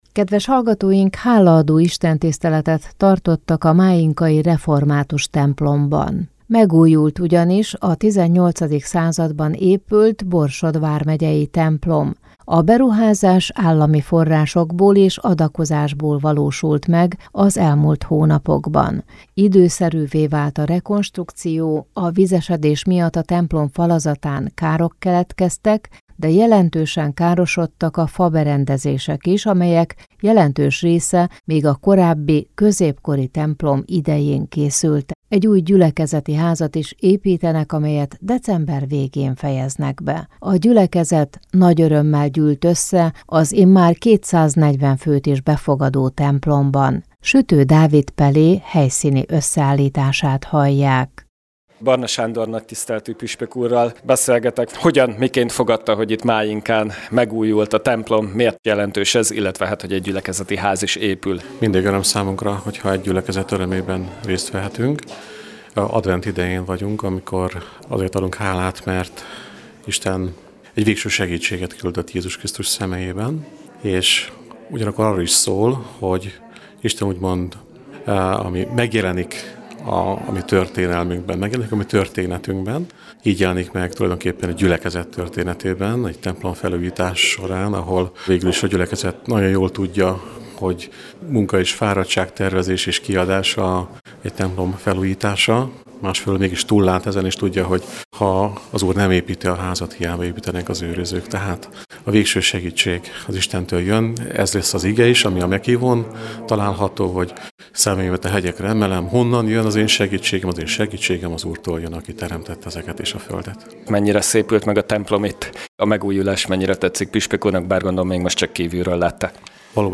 Istentiszteleten adtak hálát a 18. században épült templom megújulásáért Mályinkán
Hálaadó istentisztelet tartottak a mályinkai református templomban. Megújult ugyanis a 18. században épült borsod vármegyei templom. A beruházás állami forrásokból és adakozásból valósult meg az elmúlt hónapokban.